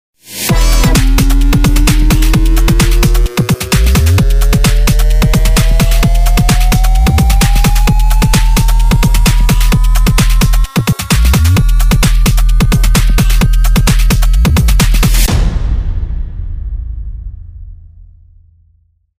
Anhänge BassSinusTest.mp3 302,5 KB · Aufrufe: 496
Dran denken dann den Sinus per Sidechain zu Ducken , mache ich mit dem Volume Shaper und triggere per Midi. Den klick nehme ich von einer normalen Bassdrum nur den Anfang den Bassbauch cutte ich dann weg.